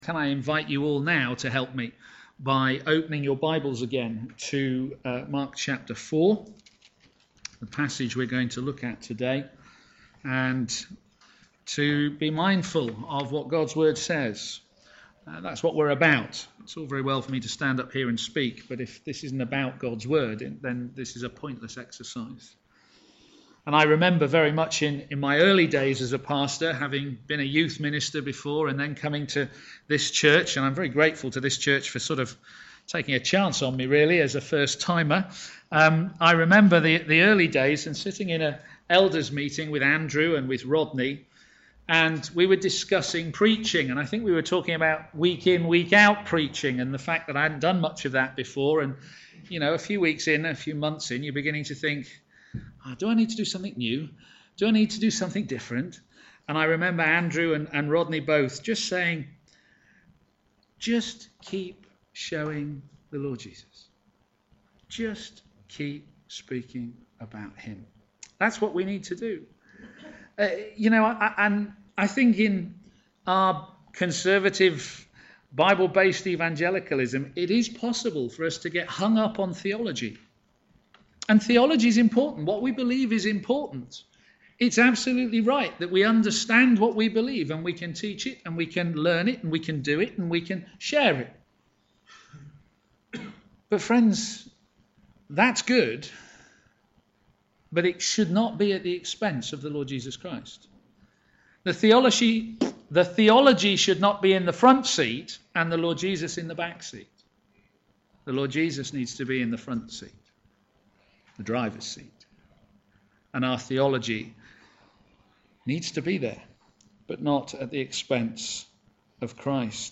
Media for a.m. Service
The Ruler of the Waves Sermon